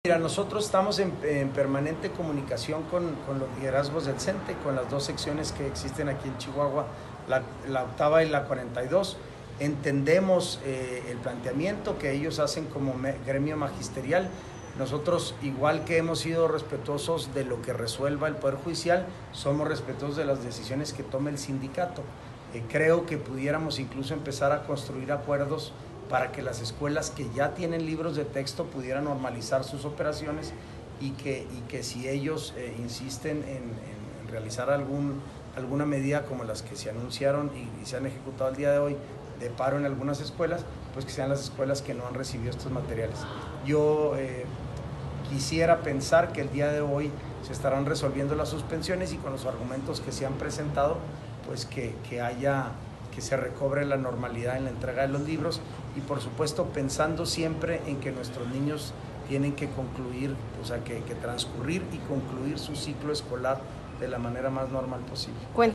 AUDIO: SANTIAGO DE LA PEÑA, SECRETARÍA GENERAL DE GOBIERNO